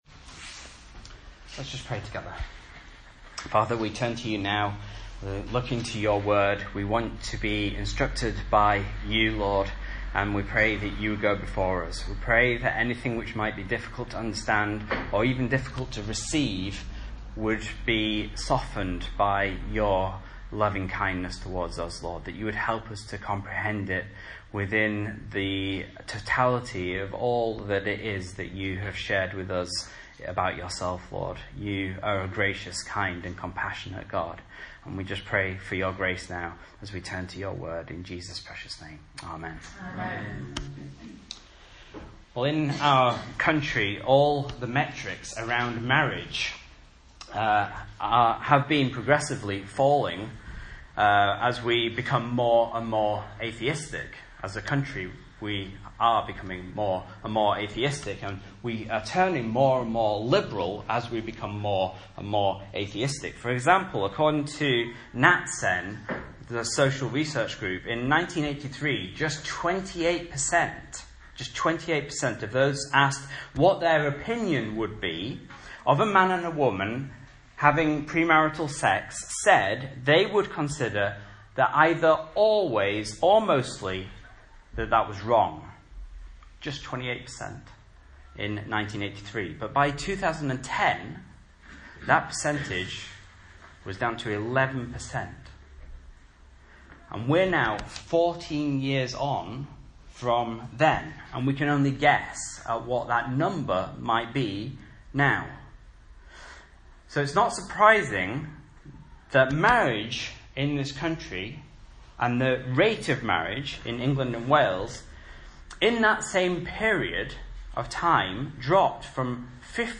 Message Scripture: Genesis 2:18-25 | Listen